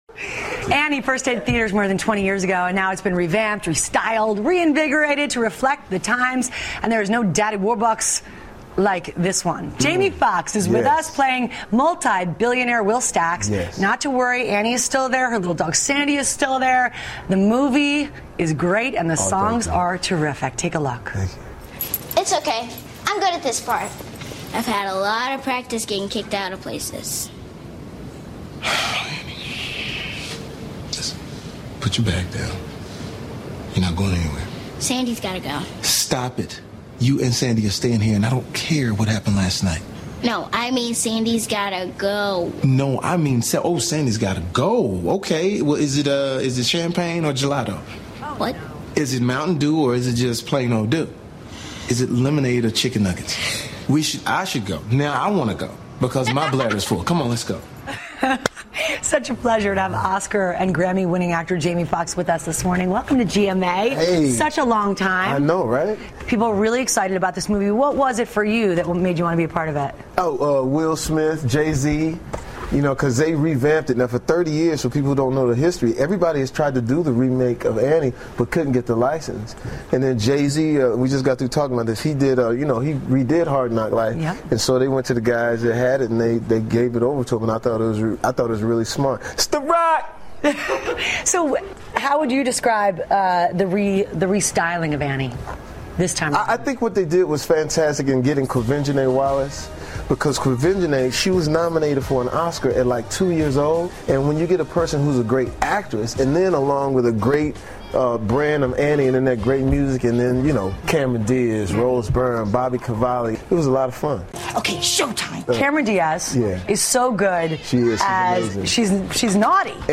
访谈录 2014-12-25&12-27 杰米·福克斯谈歌舞片《安妮》 听力文件下载—在线英语听力室